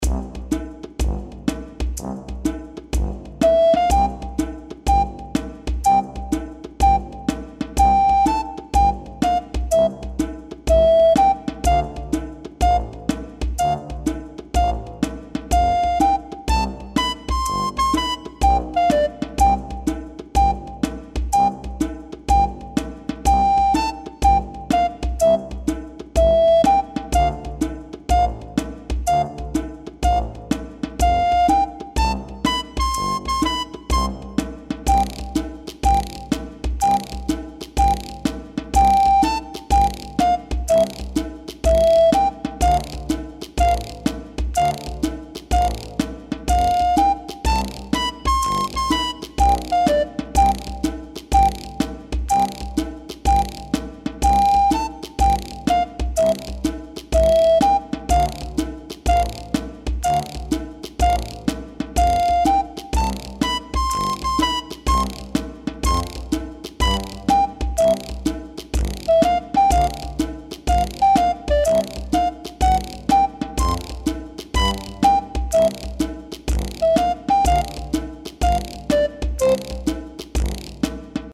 ファンタジー系フリーBGM｜ゲーム・動画・TRPGなどに！
中華風マンボ（意味不明）いい感じのタイミングで掛け声を入れてみてね。